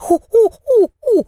monkey_chatter_15.wav